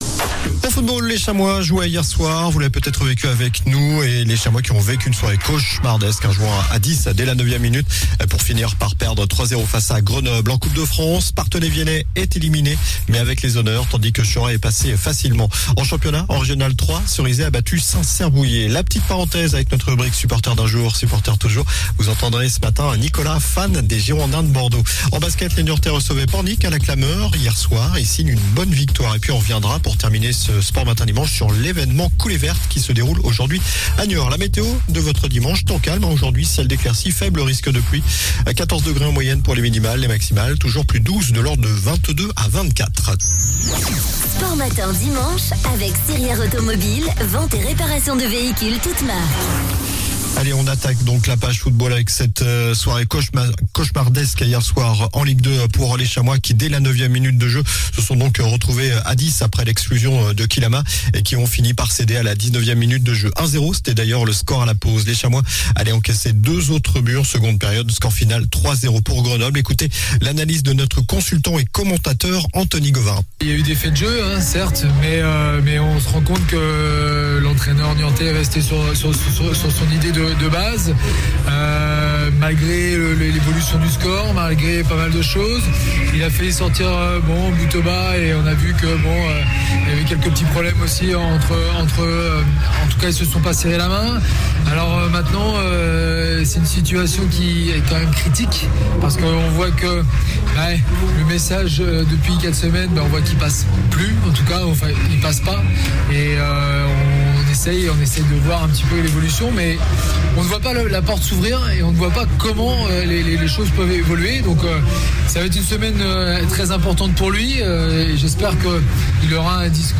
sports infos